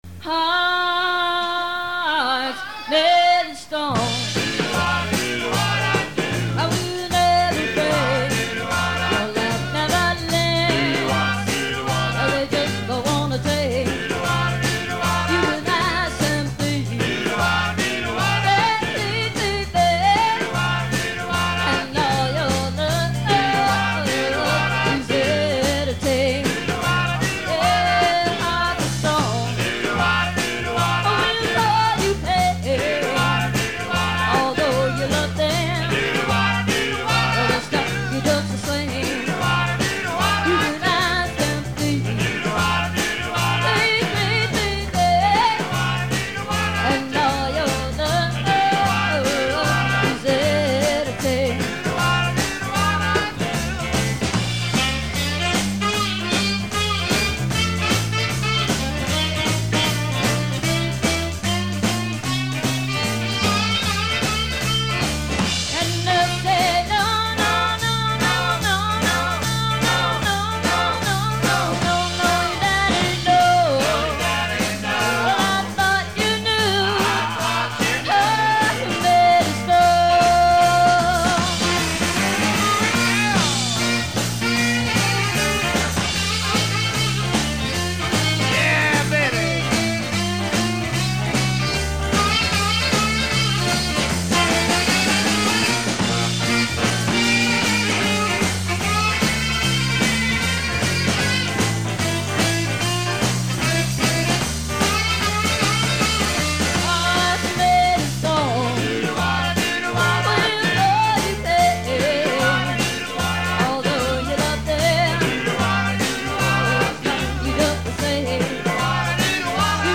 in a medley with...